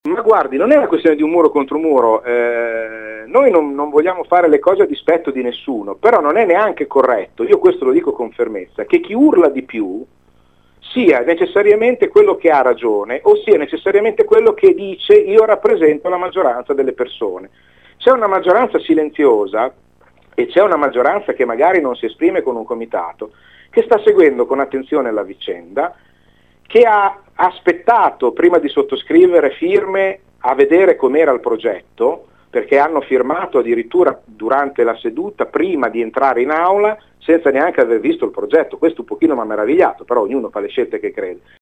12 lug. – “No chi a urla di più”. Ai nostri microfoni Vincenzo Naldi, presidente del quartiere Reno, ha stoppato le richieste del locale comitato che vuole fermare la costruzione di 60 parcheggi nel parco di via Speranza.